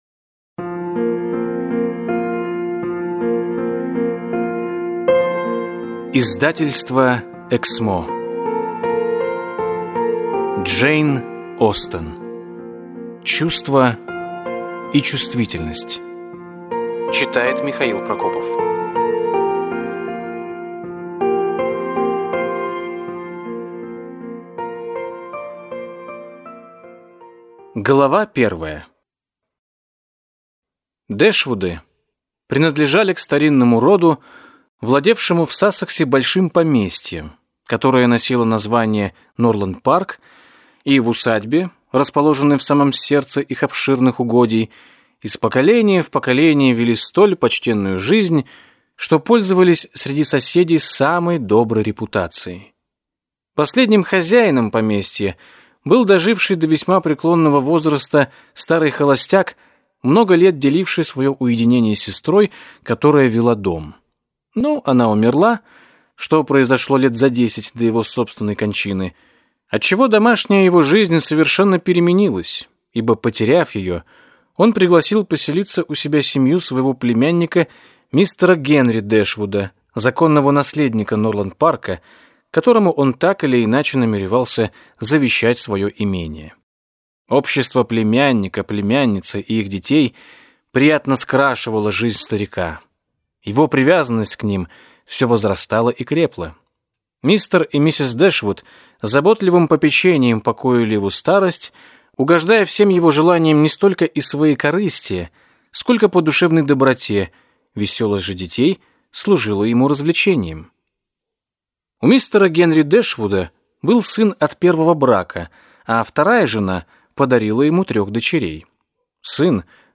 Аудиокнига Чувство и чувствительность | Библиотека аудиокниг